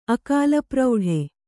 ♪ akālaprauḍhe